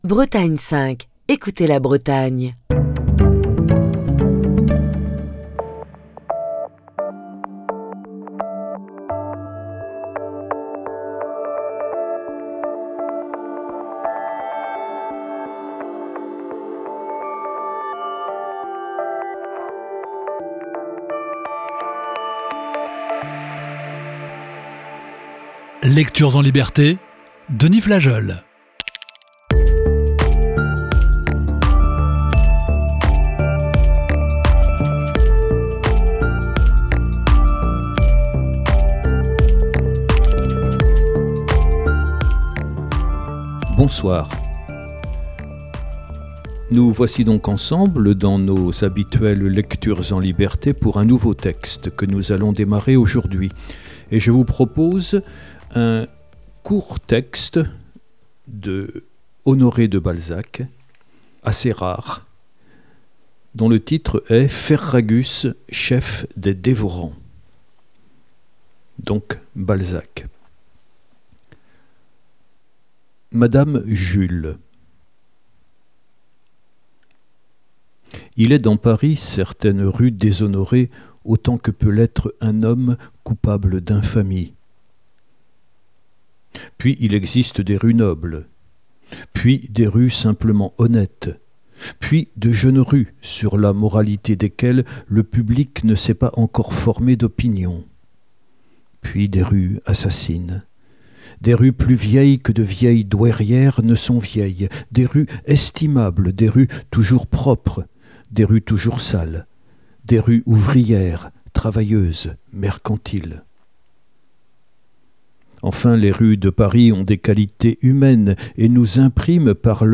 Voici ce soir la première partie de ce récit.